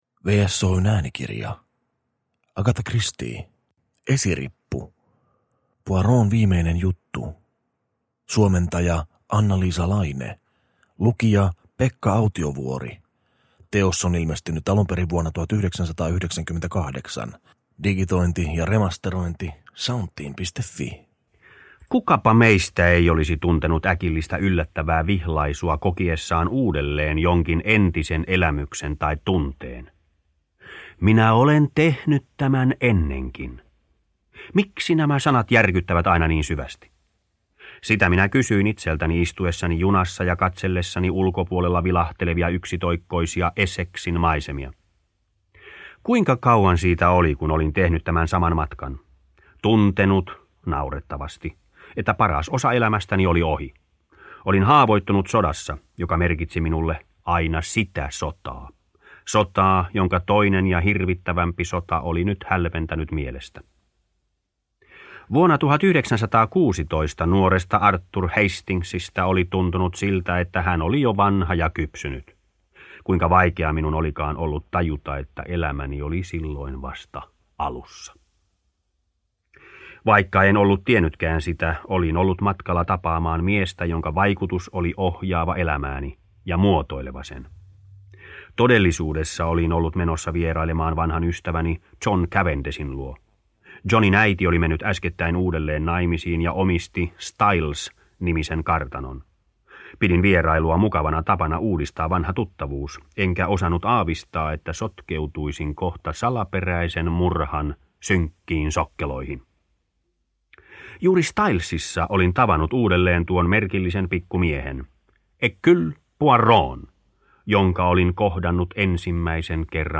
Esirippu: Poirotin viimeinen juttu (ljudbok) av Agatha Christie